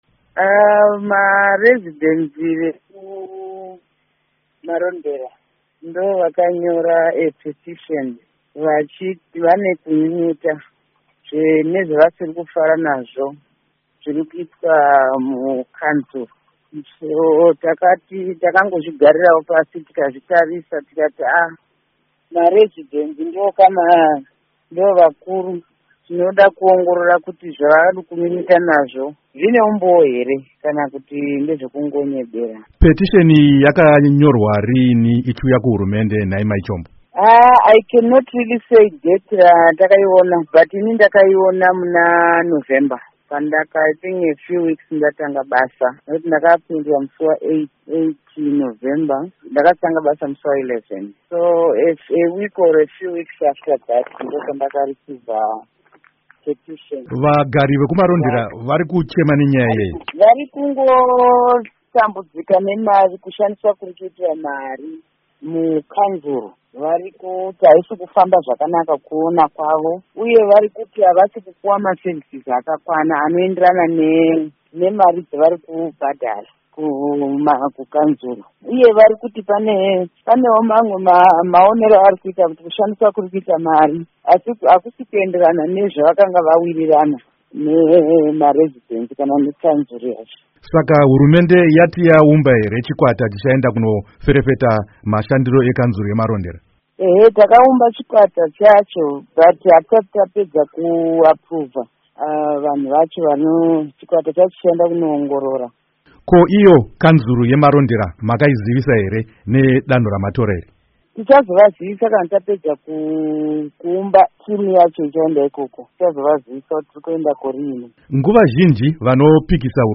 Hurukuro naAmai Marian Chombo